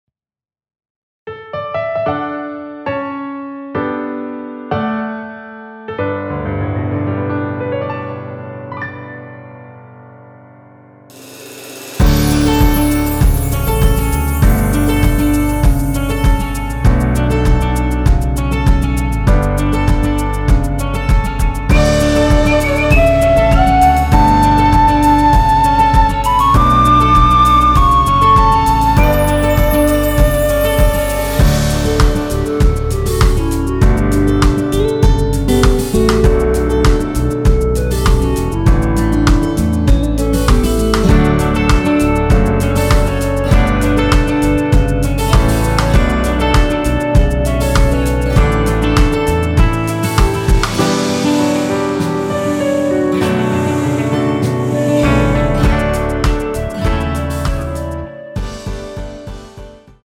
원키에서(-6)내린 (1절삭제) 멜로디 포함된 MR입니다.
D
앞부분30초, 뒷부분30초씩 편집해서 올려 드리고 있습니다.